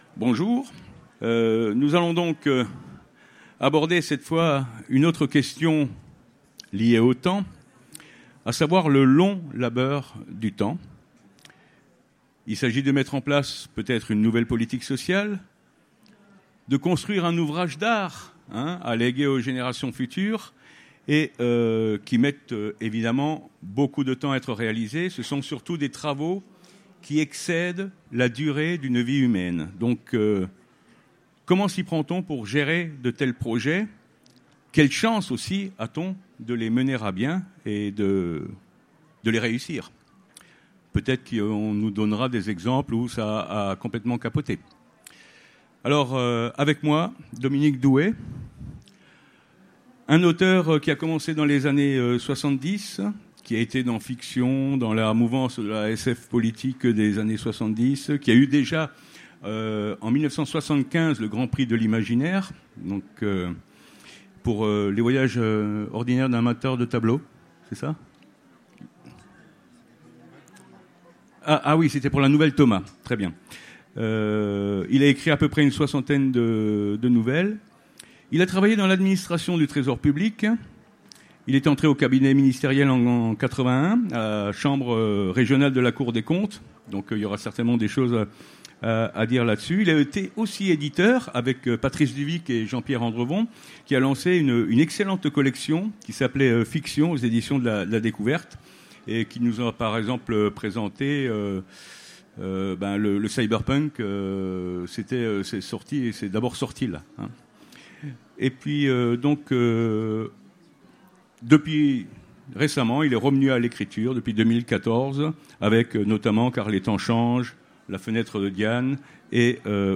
Utopiales 2017 : Conférence Le long labeur du temps